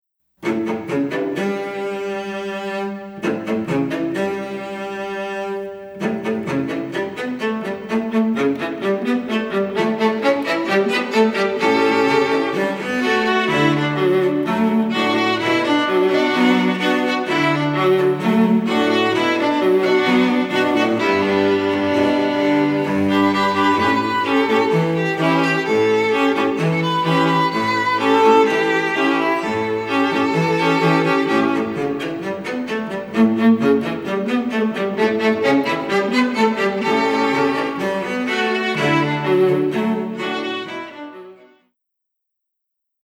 Voicing: String Quartet